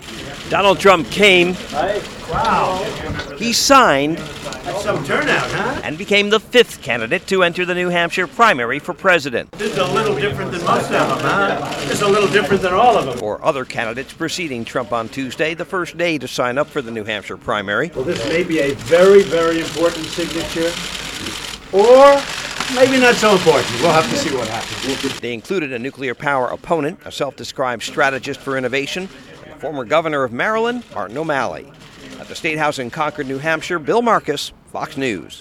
(CONCORD, NH) NOV 4 – INTO A ROOM PACKED WITH MEDIA, G-O-P FRONT RUNNER DONALD MAKING IT OFFICIAL IN CONCORD, NEW HAMPSHIRE AND ENTERING THAT STATE’S FIRST IN THE NATION PRIMARY NOW SCHEDULED FOR TUESDAY, FEBRUARY 9TH.